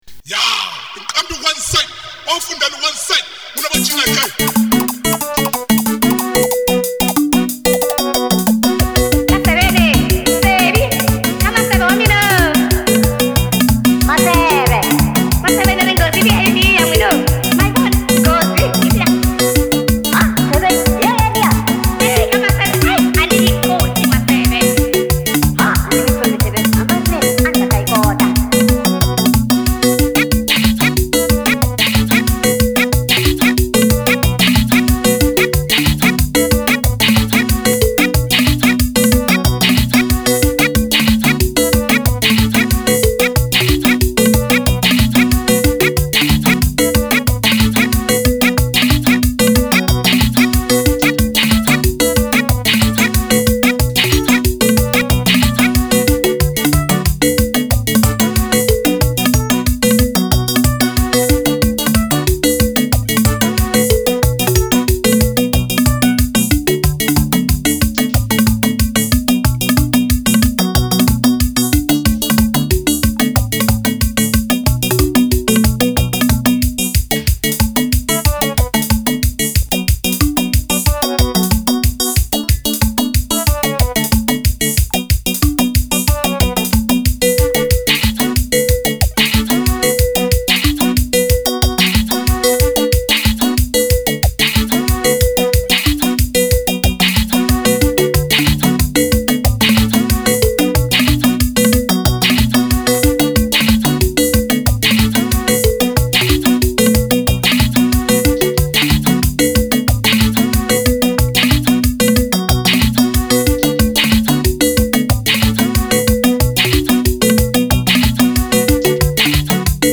Shangaan Electro